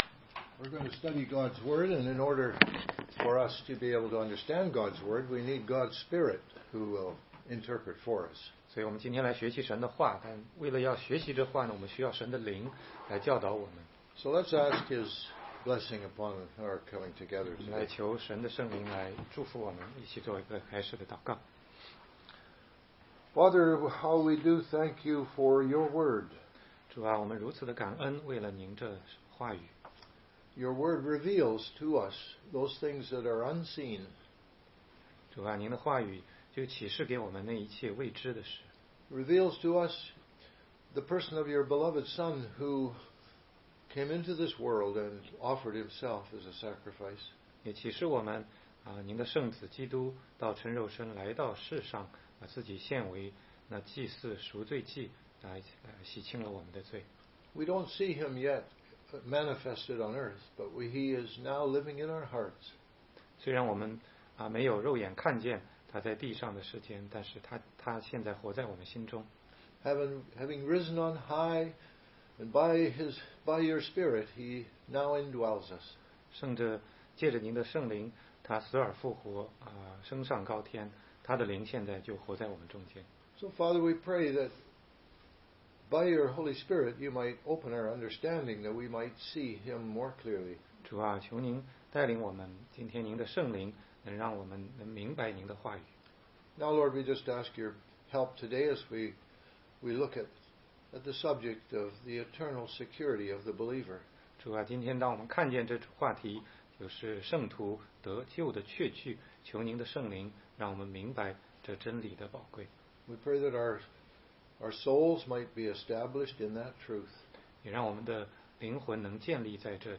16街讲道录音 - 约翰福音10章救恩永不会失去系列之一